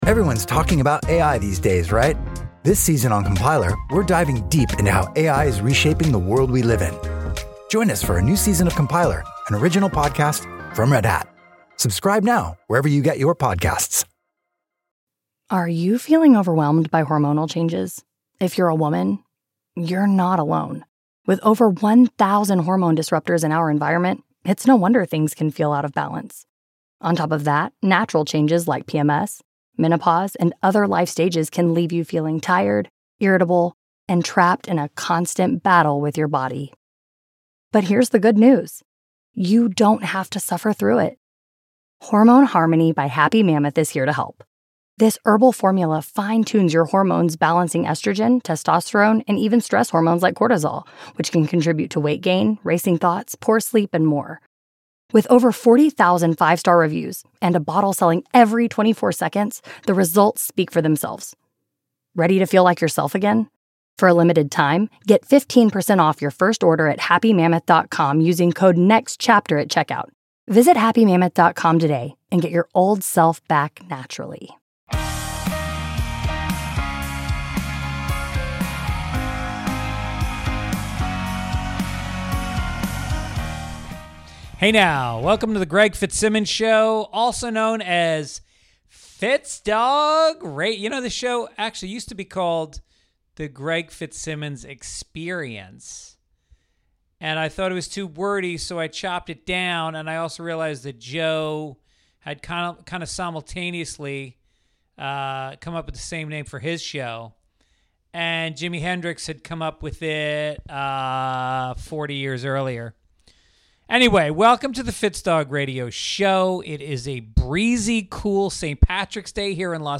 Honest funny interviews w/ Greg Fitzsimmons' new and old friends diving deep and laughing hard.